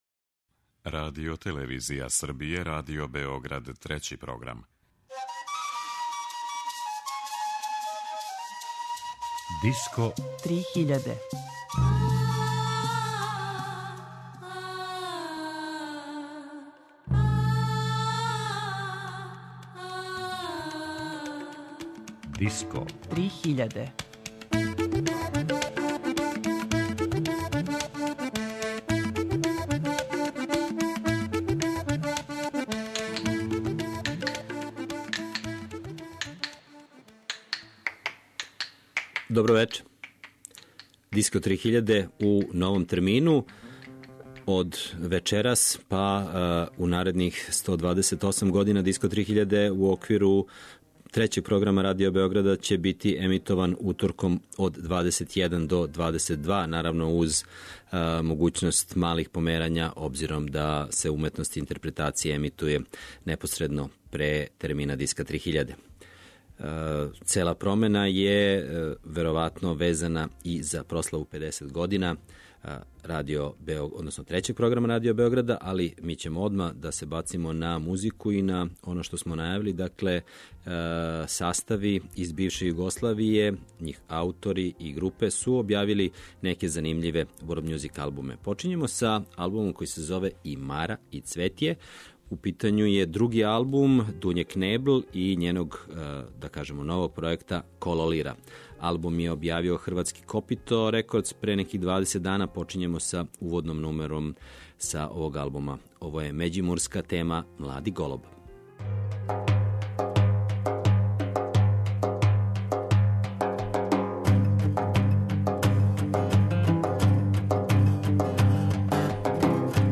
Аутори и ансамбли из БиХ, Хрватске и Србије.